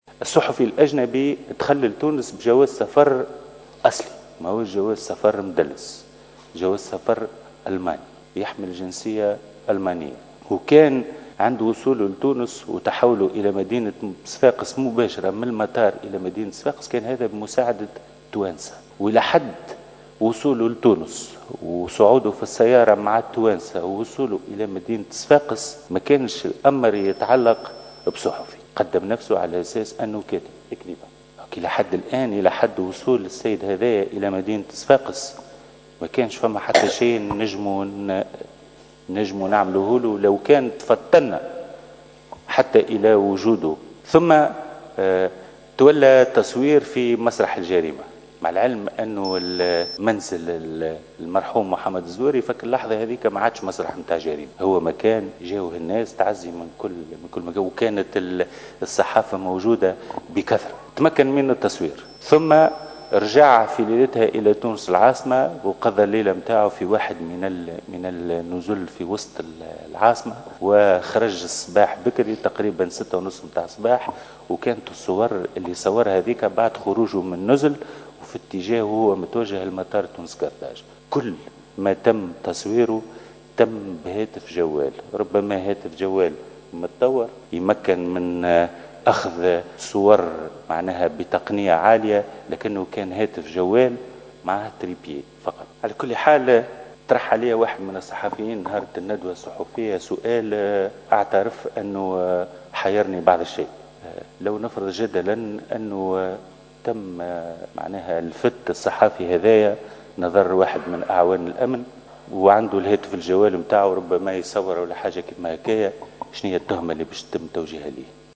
و أضاف المجدوب في ردّه على تساؤلات النواب في جلسة عامة استثنائية عُقدت اليوم، تمّ تخصيصها لملف اغتيال الشهيد محمد الزواري ، أنّ هذا الصحفي و فوْر وصوله إلى تونس تحول مباشرة إلى مدينة صفاقس على متن سيّارة بمساعدة تونسيين وعرّف نفسه بصفته كاتبا و ليس صحفيا ثم تولى التصوير في مسرح جريمة اغتيال محمد الزواري، مشيرا إلى أنّ منزل المهندس التونسي لم يعد حينها يخضع لقواعد منع التصوير لأنه أصبح مفتوحا للجميع، مبيّنا أنّ الصحفي الإسرائيلي استعمل في التصوير هاتفا جوالا متطورا ذات جودة عالية مستعينا بـ"مثبّت صورة" ولم يقم بالبث المباشر عبر الأقمار الاصطناعية كما ذكرت إحدى النائبات.